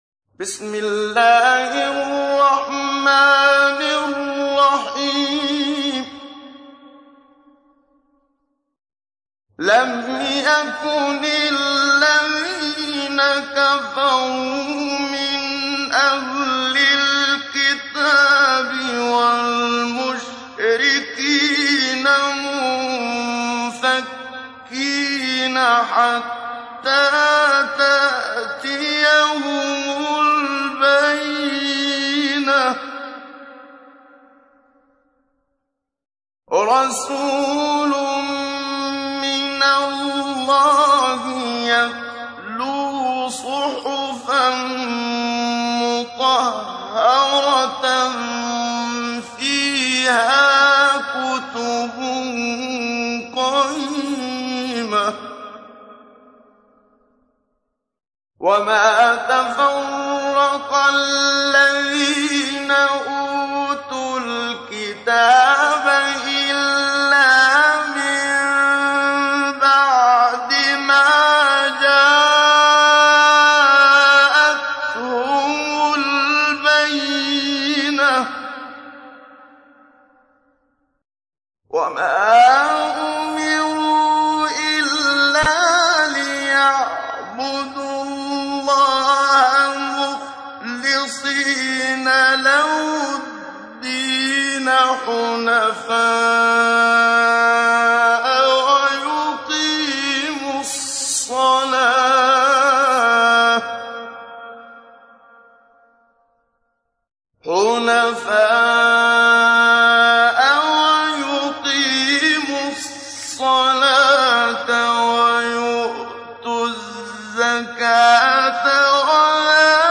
تحميل : 98. سورة البينة / القارئ محمد صديق المنشاوي / القرآن الكريم / موقع يا حسين